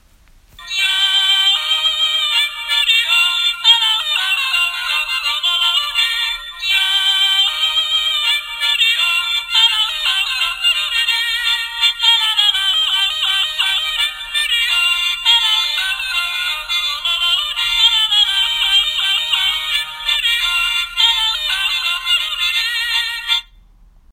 Marmotte peluche chante du yodle
Lorsque vous pressez sur son ventre, la marmotte chante du jodle !
Son joué par la marmotte quand on presse son ventre (cliquer sur le triangle ci-dessous pour écouter):
Jodel_2952V.m4a